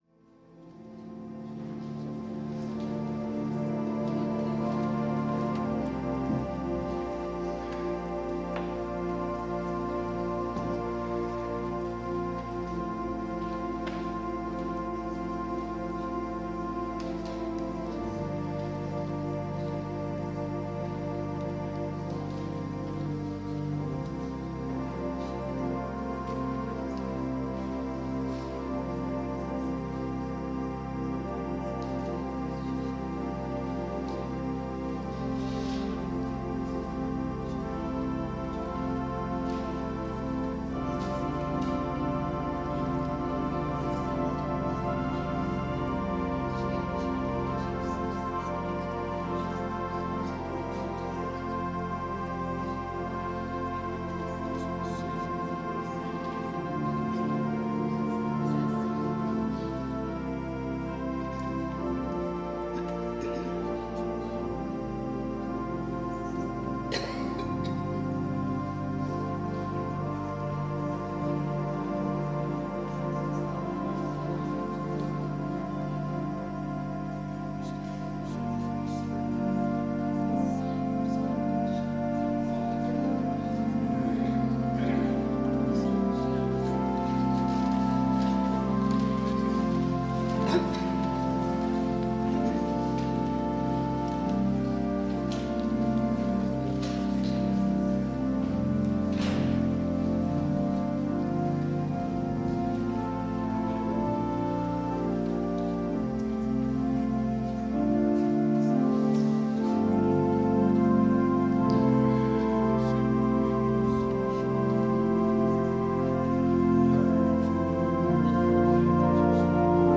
Worship and Sermon audio podcasts
WORSHIP - 10:30 a.m. First Sunday after Christmas